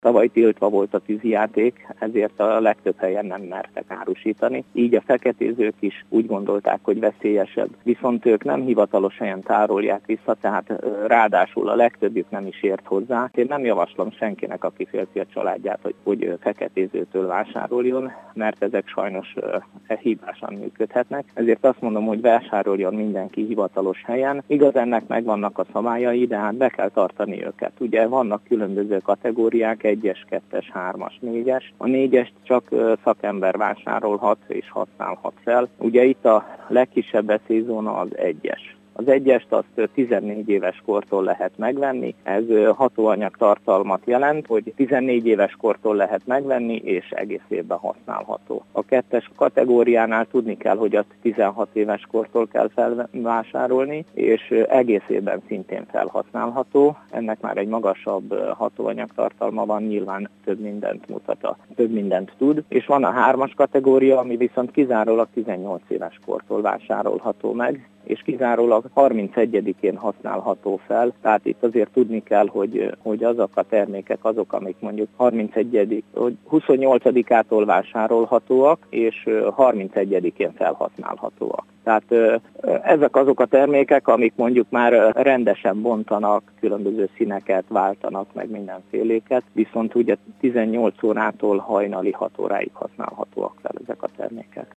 kereskedőt hallják.